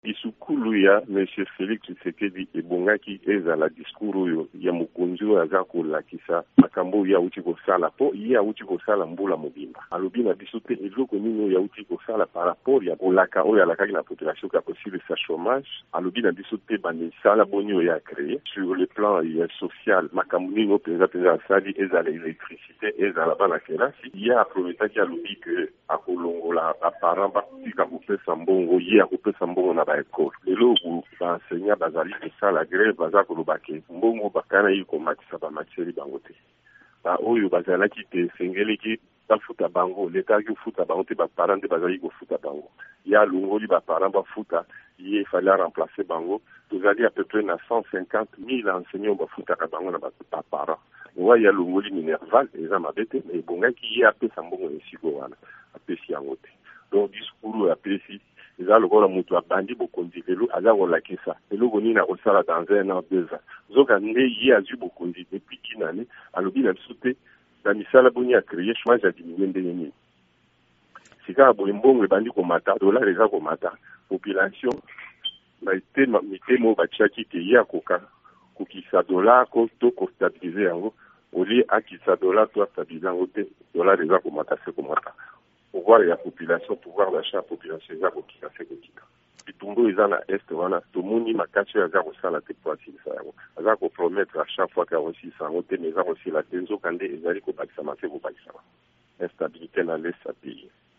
VOA Lingala epesaki maloba na Adolphe Muzito.